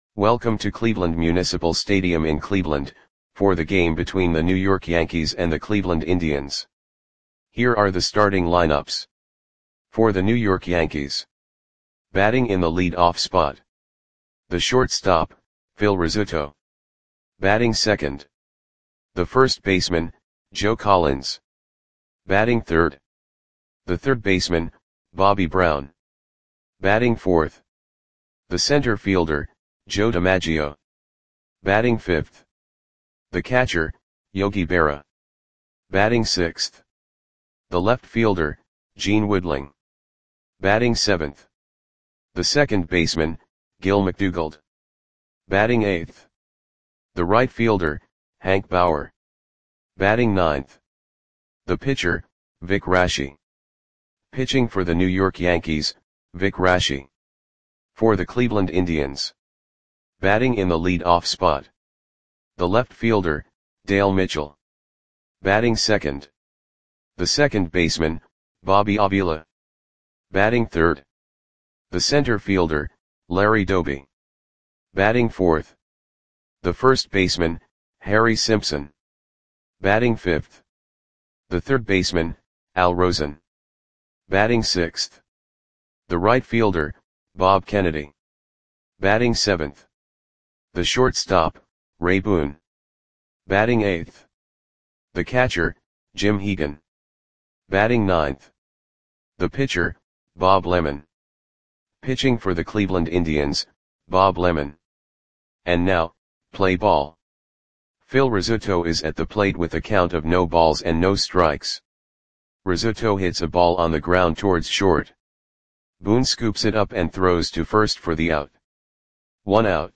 Audio Play-by-Play for Cleveland Indians on August 23, 1951
Click the button below to listen to the audio play-by-play.